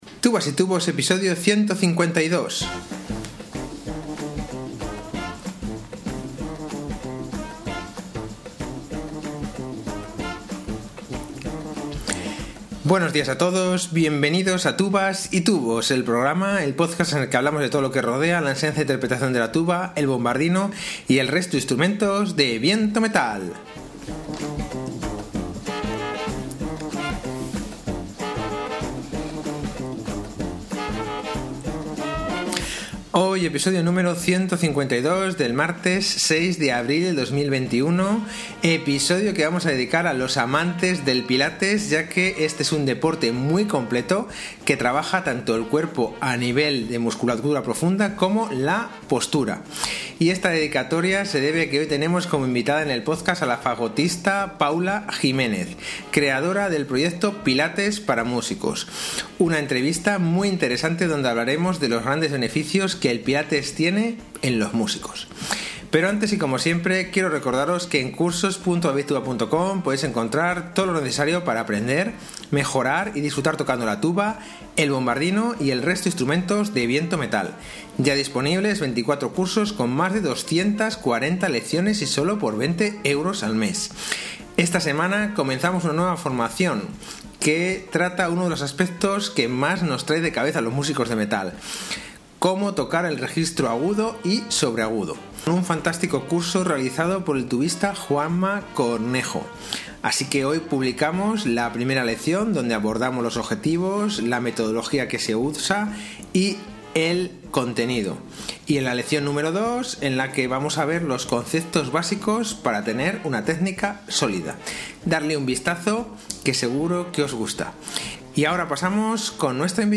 una entrevista muy interesante donde hablaremos de los grandes beneficios que el pilates tiene en los músicos.